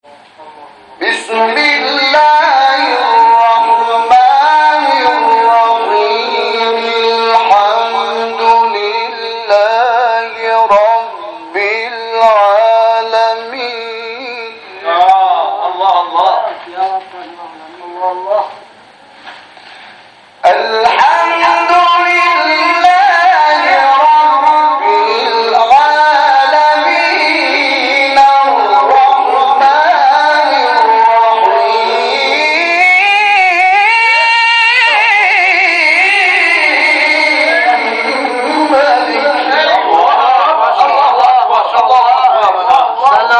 گروه فعالیت‌های قرآنی: جدیدترین مقاطع صوتی تلاوت شده توسط قاریان ممتاز کشور را می‌شنوید.
مقطع سوره حمد به سبک حصان